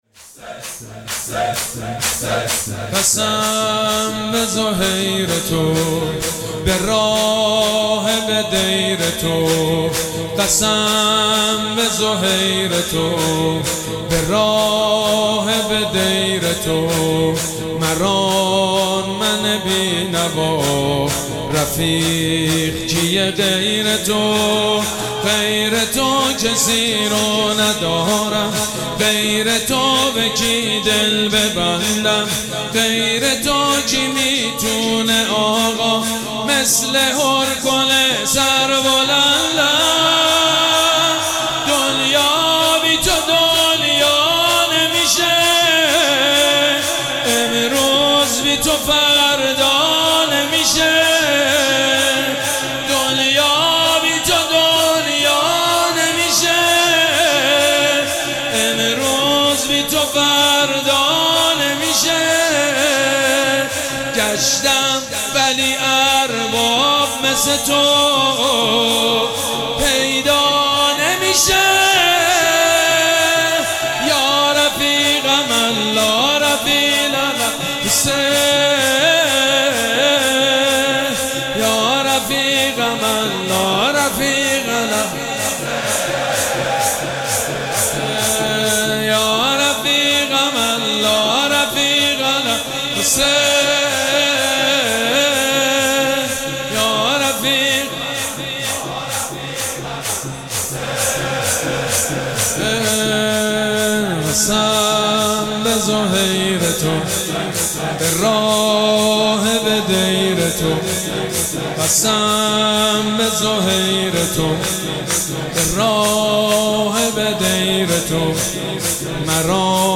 مراسم عزاداری شب ششم محرم الحرام ۱۴۴۷
شور
مداح
حاج سید مجید بنی فاطمه